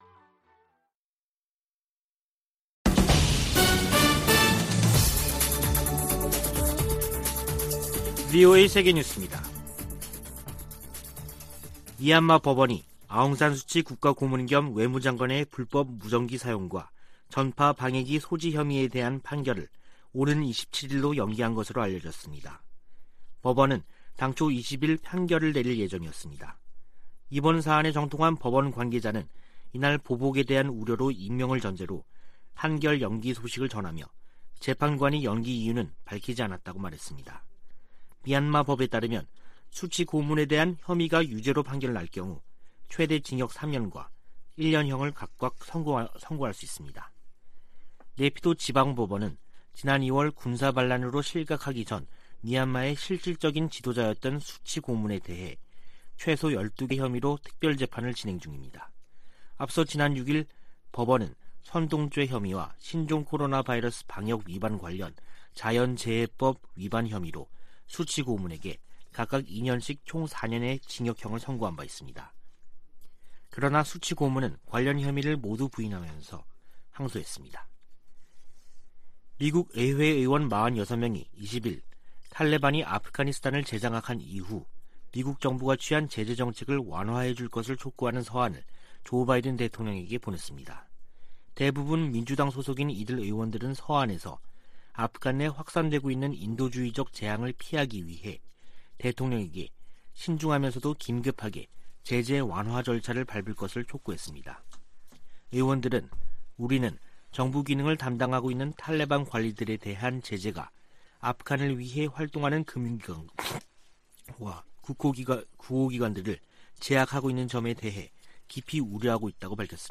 VOA 한국어 간판 뉴스 프로그램 '뉴스 투데이', 2021년 12월 21일 3부 방송입니다. 북한은 미사일 개발 등 대규모 군사비 지출로 만성적인 경제난을 겪고 있다고 미 CIA가 분석했습니다. 미국의 전문가들은 북한이 ICBM 대기권 재진입과 핵탄두 소형화 등 핵심 기술을 보유했는지에 엇갈린 견해를 내놓고 있습니다. '오미크론' 변이 출현으로 북-중 교역 재개가 지연됨에 따라 북한 주민들이 겨울나기에 한층 어려움을 겪고 있습니다.